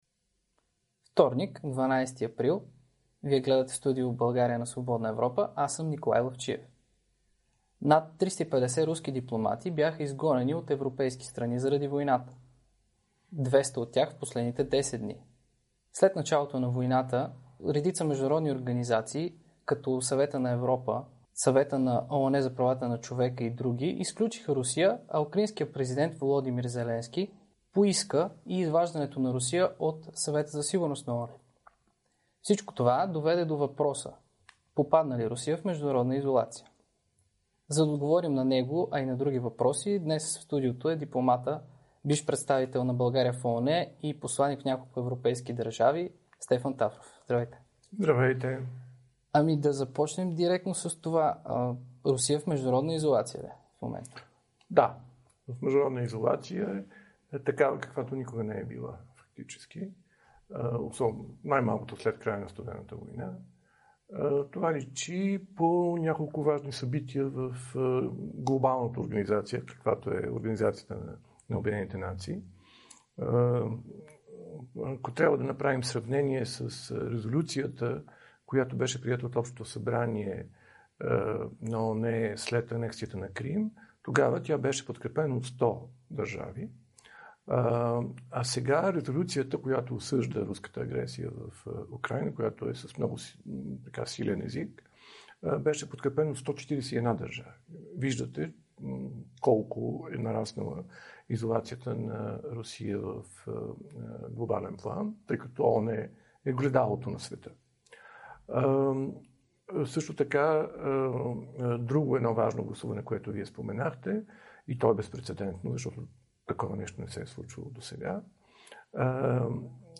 Чуйте дипломата Стефан Тафров пред Свободна Европа
Това каза дипломатът Стефан Тафров в Студио България на Свободна Европа. Посланик Митрофанова се отличава в негативен смисъл спрямо своите колеги руски посланици в другите европейски страни, каза още той.